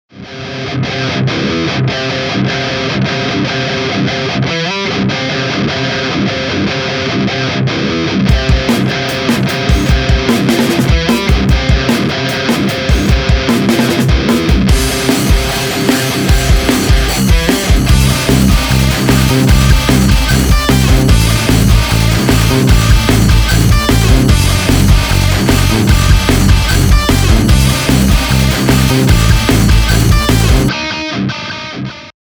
В наличии Focusrite Scarlett 18i6, старенький джэксон с пассивными датчиками, Kemper Profiling Amplifier.
да вроде нормально все. низ отрезать желательно, нижнюю середину поджать обязательно, выпрыгивает. остальное по вкусу и задачам. например: Вложения NEW002.mp3 NEW002.mp3 1,2 MB · Просмотры: 227